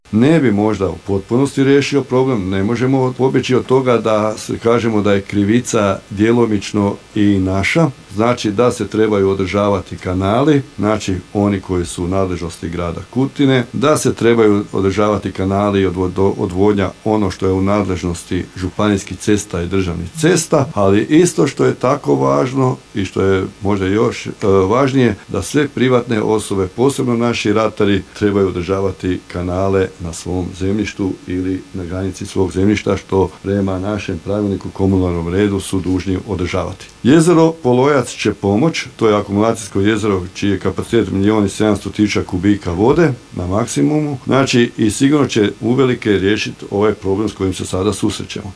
Gradonačelnik Kutine Zlatko Babić osvrnuo se na nedavnu elementarnu nepogodu te na akumulaciju Polojac koja bi pomogla pri rješavanju problema plavljenja grada